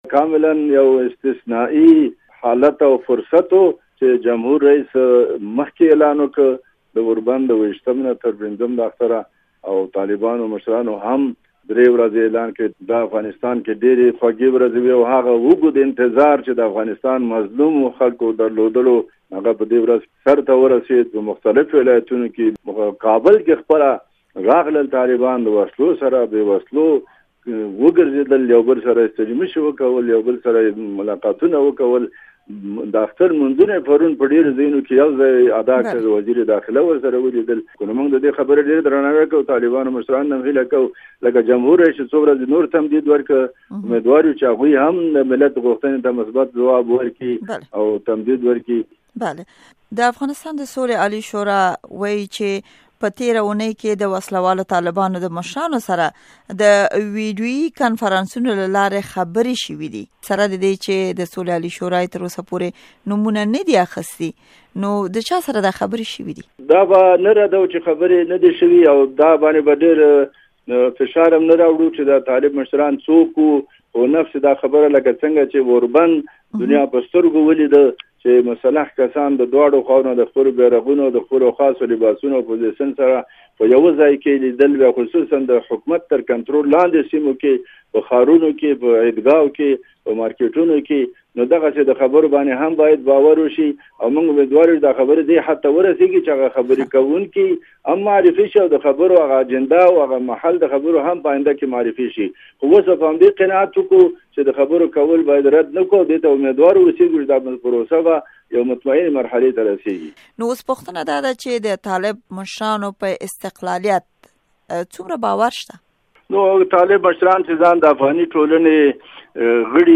امريکا غږ سره د قاضي محمد امين وقاد مرکه